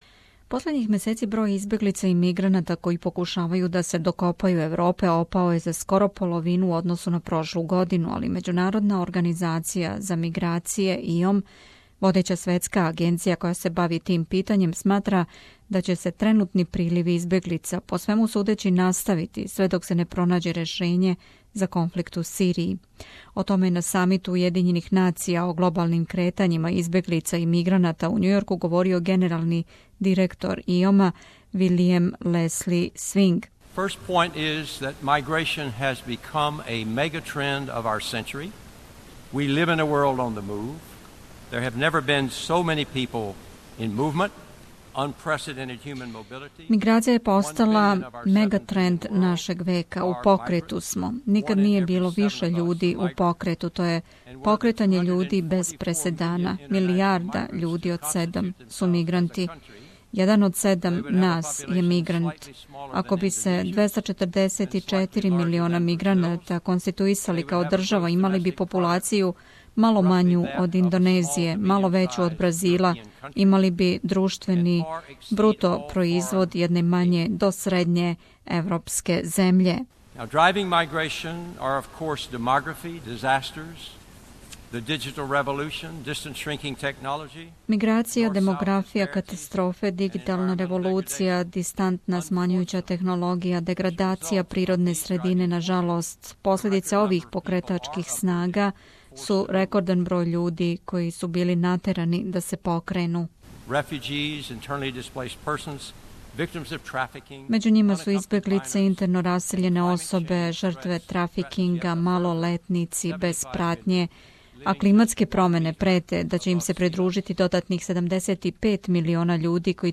О томе је на самиту УН о глобалним кретањима избеглица и миграната у Њујорку говорио генерални директор ИОМ-а Вилијам Лејси Свинг.